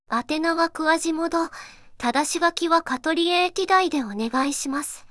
voicevox-voice-corpus
voicevox-voice-corpus / ROHAN-corpus /四国めたん_セクシー /ROHAN4600_0014.wav